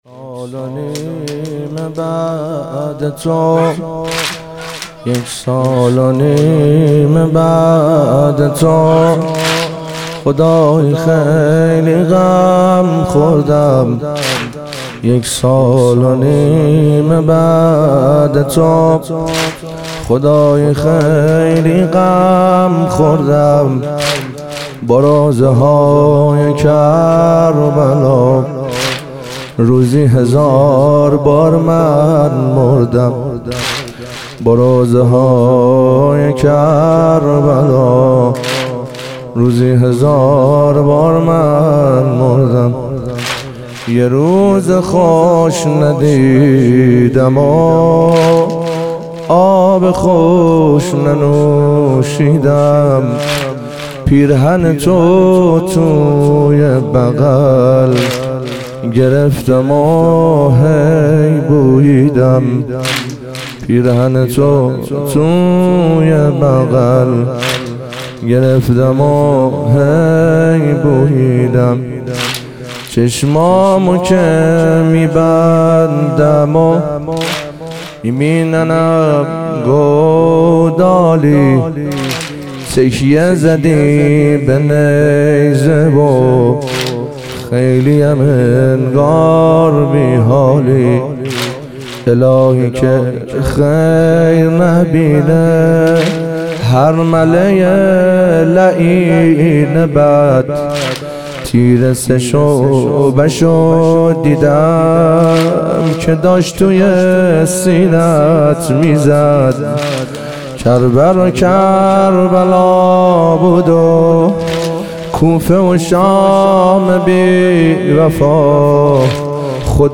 ظهور وجود مقدس حضرت زینب علیها سلام - واحد